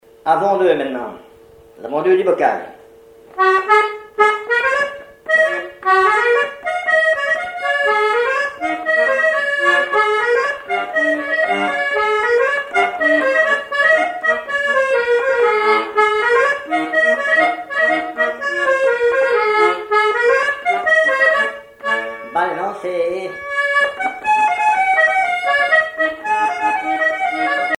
accordéon(s), accordéoniste
danse : branle : avant-deux
Pièce musicale inédite